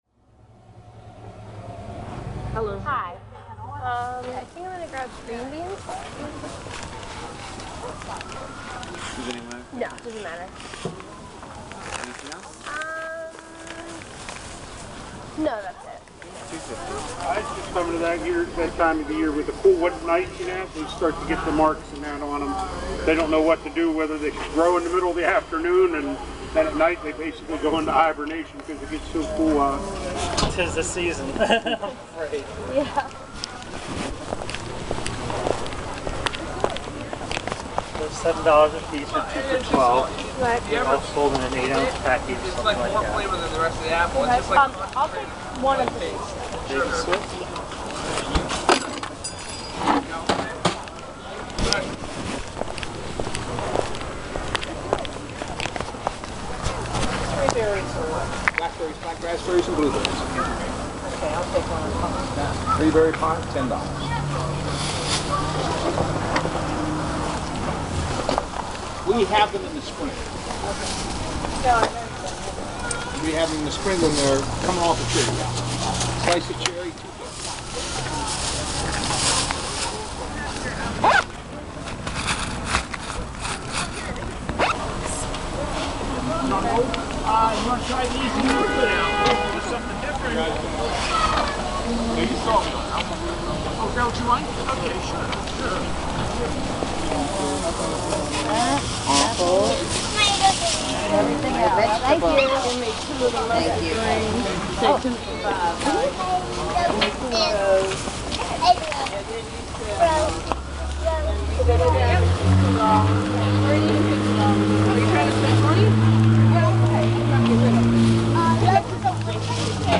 Final Soundscape
South Oakland Farmers Market
Sirens and helicopters drown out the quiet Amish girls selling pies and cookies. The market attracts all different breeds of people, from college students buying tonight’s dinner to elderly women in search of the perfect tomato. The mixtures of urban and organic; old and youthful; noisy and timid make for a very strange soundscape.